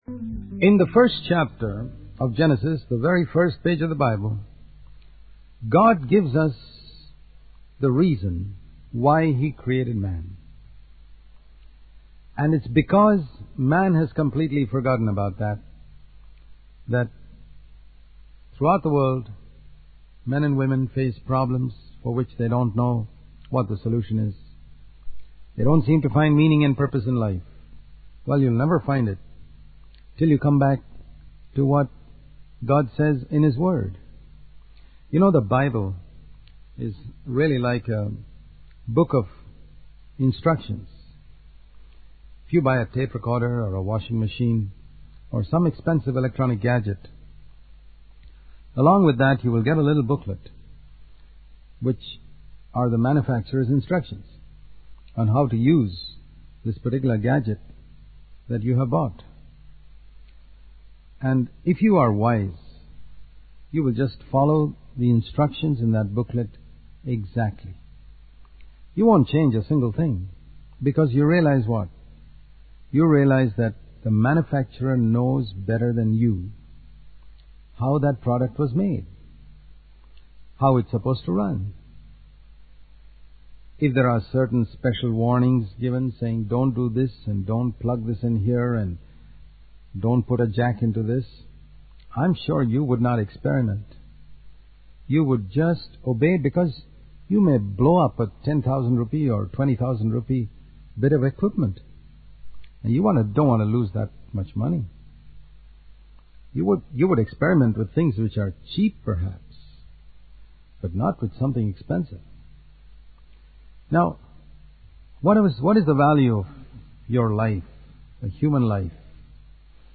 In this sermon, the speaker emphasizes the importance of living according to the example set by Jesus Christ. He compares the ineffective method of teaching swimming through instructions on a blackboard to the powerful impact of learning through example.